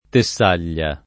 [ te SS# l’l’a ]